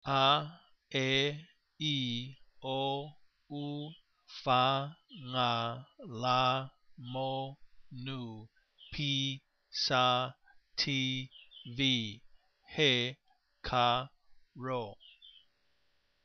Usounds like o'o in too
Gsounds like 'ng' as in sing
Hear the letters pronounced.